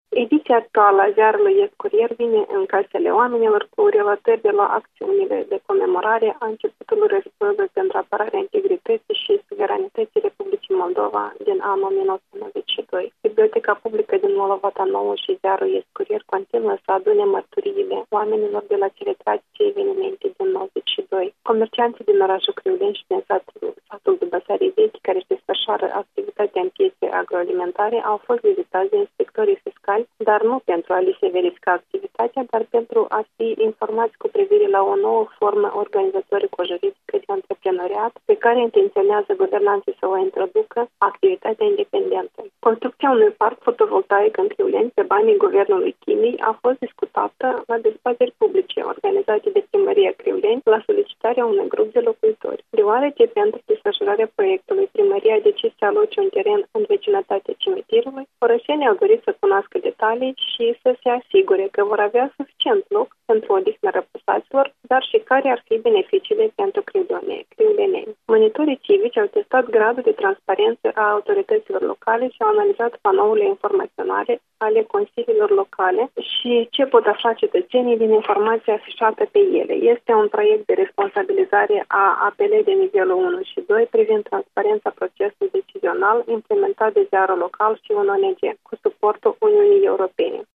Invitata de astăzi